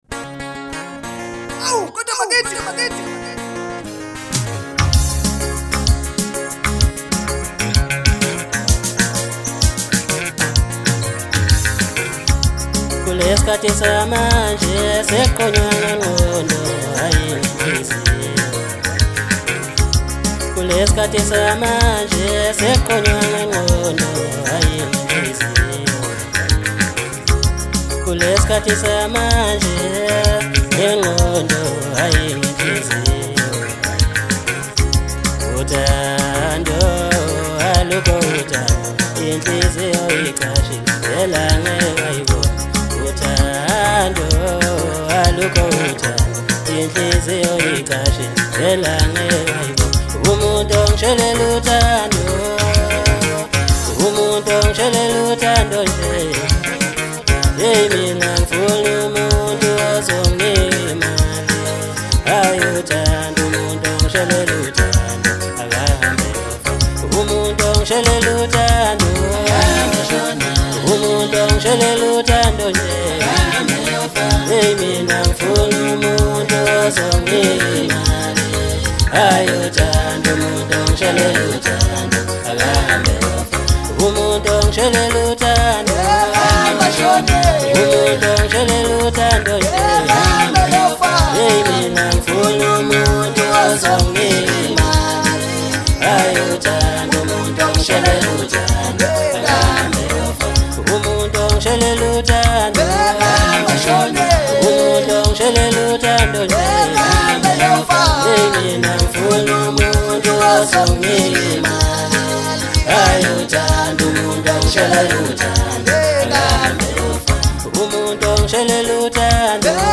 Home » DJ Mix » Hip Hop » Maskandi